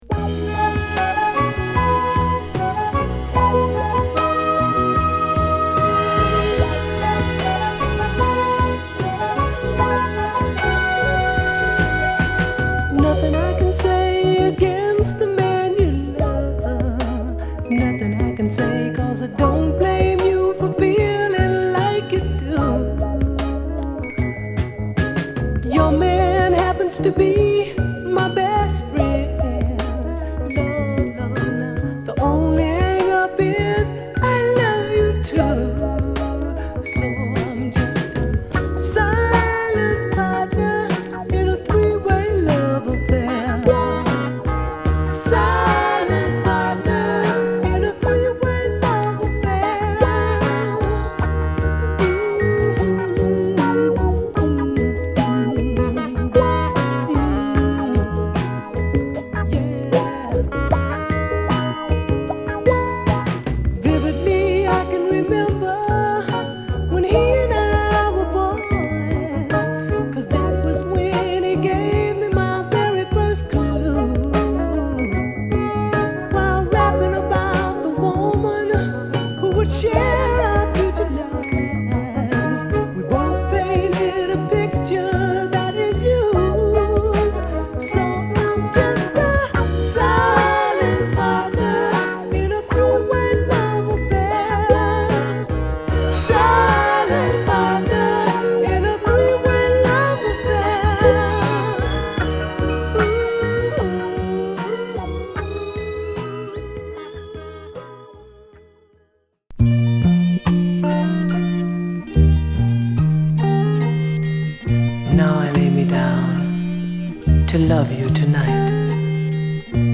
ヴォーカルの乗りもバッチリで甘めに仕上がった、ナイスソウルアルバムです！